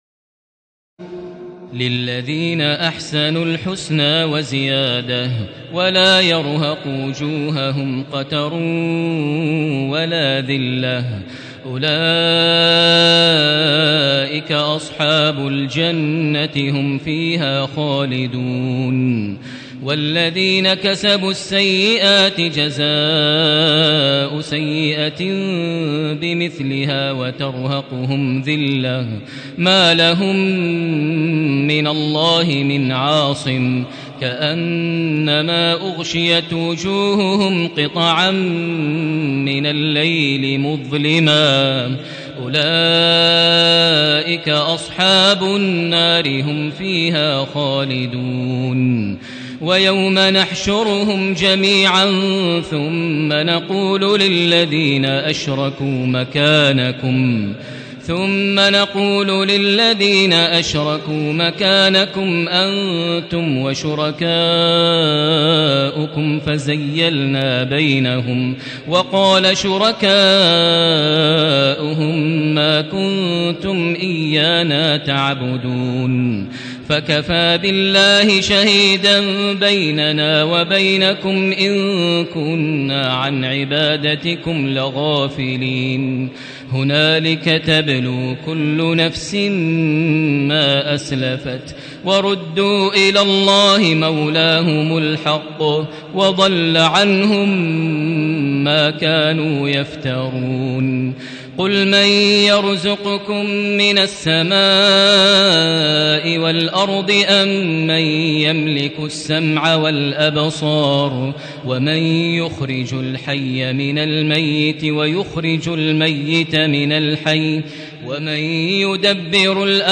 تراويح الليلة العاشرة رمضان 1437هـ من سورتي يونس (26-109) و هود (1-5) Taraweeh 10 st night Ramadan 1437H from Surah Yunus and Hud > تراويح الحرم المكي عام 1437 🕋 > التراويح - تلاوات الحرمين